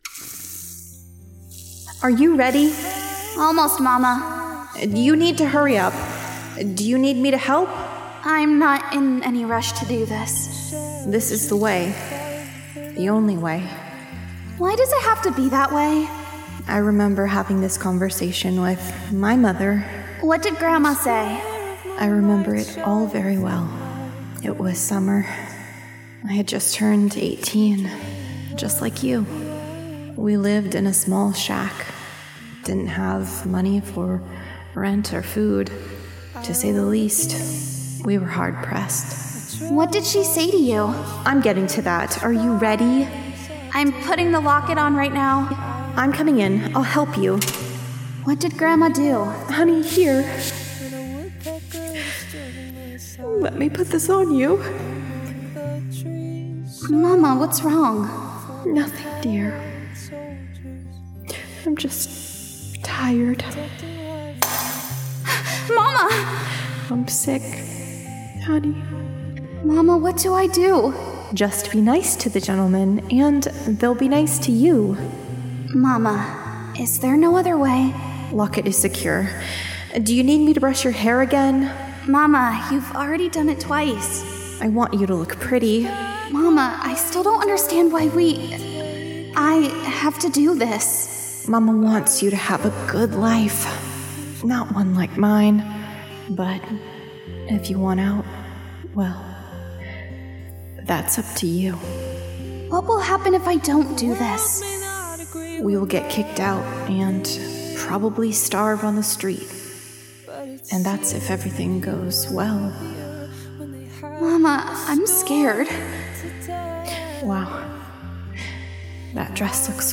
Welcome to Tales from the Janitor, a spine-chilling plunge into Oklahoma’s most unsettling urban legends, prairie-born folklore, and red-dirt hauntings… all told through the mop-wielding perspective of your eerie (and oddly well-informed) custodial guide.
Every story is soaked in atmosphere, slow dread, and that dry humor you only find from someone who’s spent too many nights sweeping empty hallways in a haunted schoolhouse.